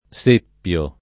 seppiare v.; seppio [ S% pp L o ], ‑pi